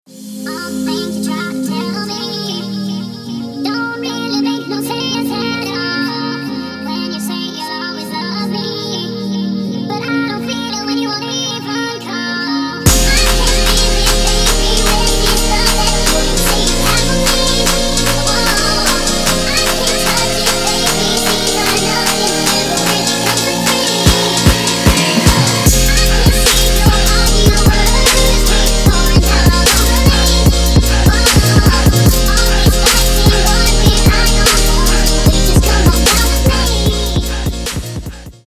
• Качество: 224, Stereo
Trap
club
vocal
трэп